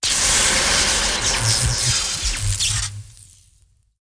SFX气流与电的魔法释放攻击技能音效下载
SFX音效